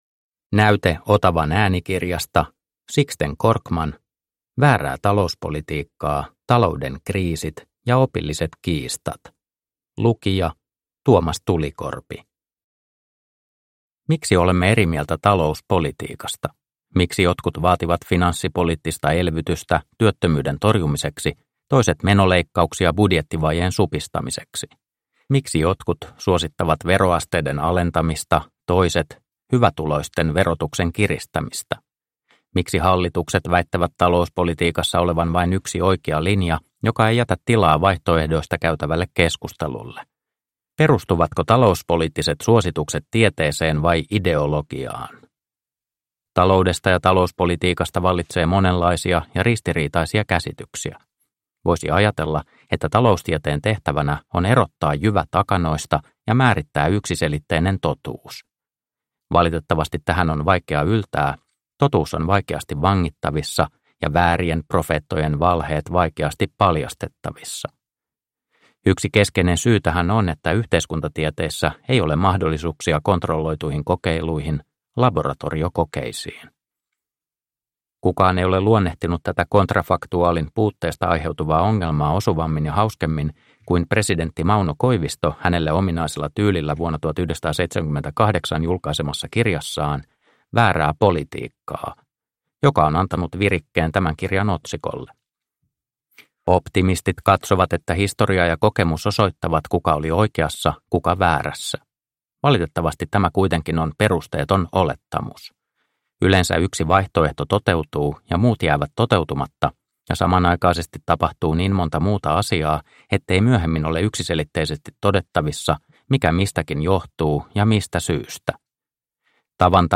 Väärää talouspolitiikkaa – Ljudbok – Laddas ner